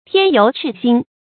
添油熾薪 注音： ㄊㄧㄢ ㄧㄡˊ ㄔㄧˋ ㄒㄧㄣ 讀音讀法： 意思解釋： 猶言火上加油。